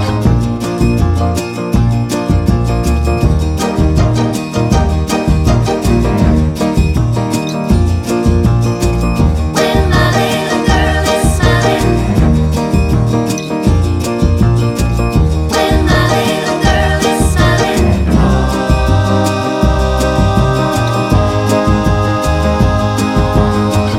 no Backing Vocals Soul / Motown 2:40 Buy £1.50